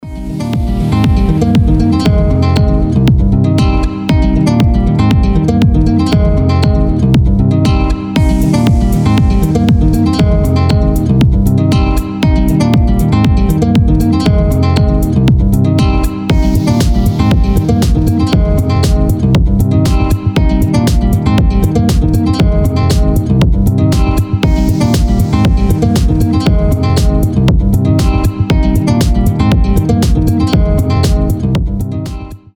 • Качество: 320, Stereo
гитара
без слов
красивая мелодия
Интересная музыка, относящаяся к армейским песням под гитару